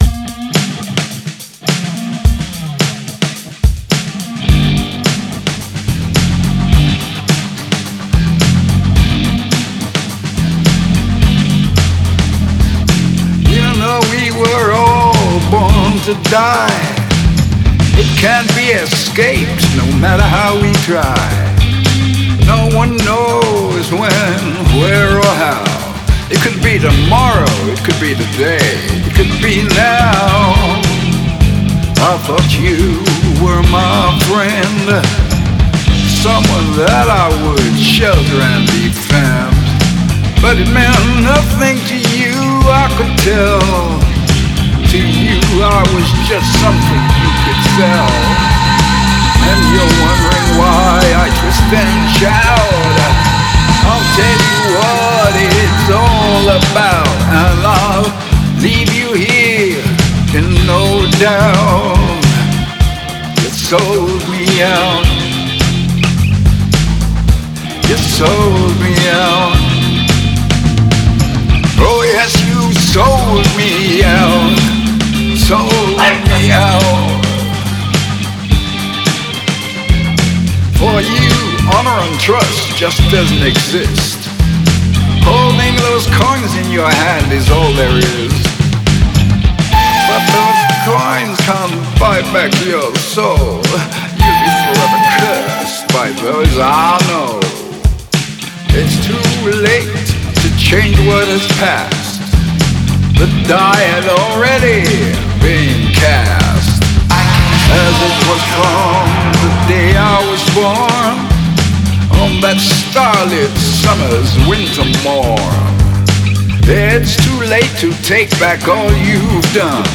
a powerful, high-stakes anthem of betrayal